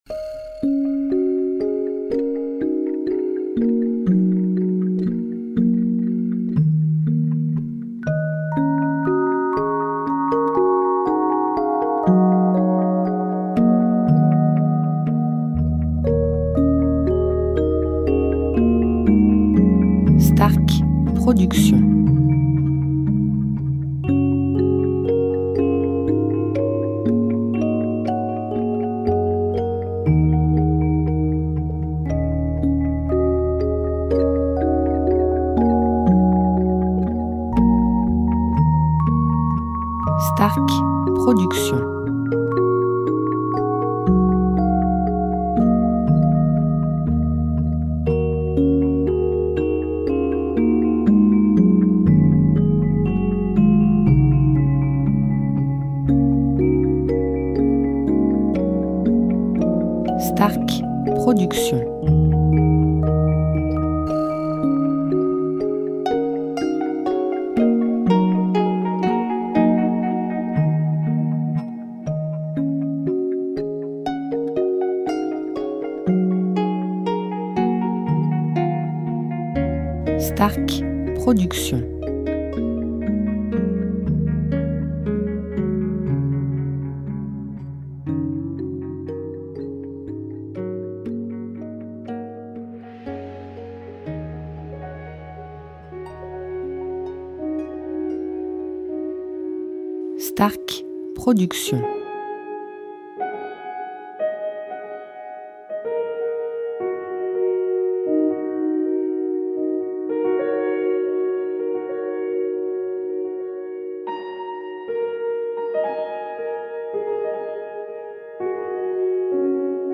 style Californien